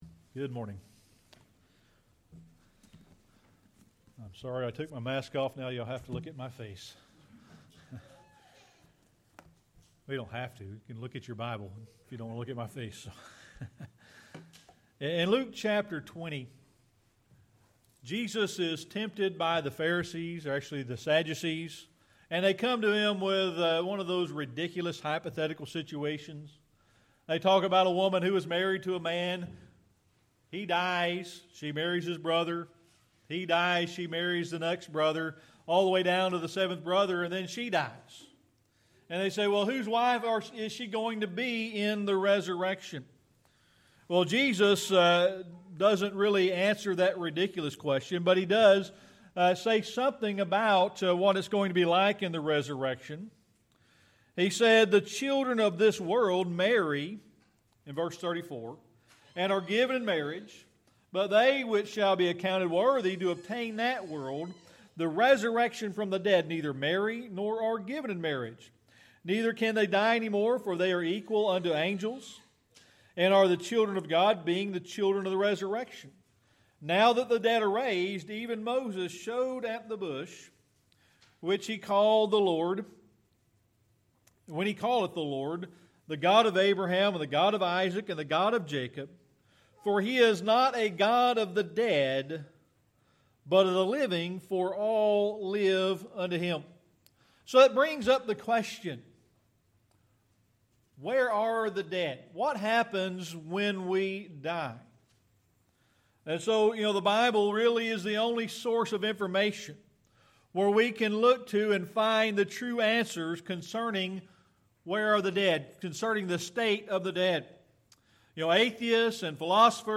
Luke 20:37-38 Service Type: Sunday Morning Worship In Luke 20